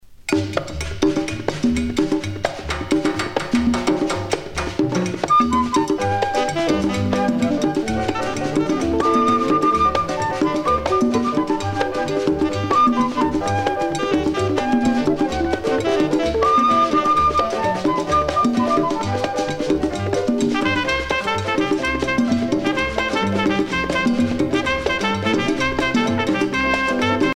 danse : guaracha
Pièce musicale éditée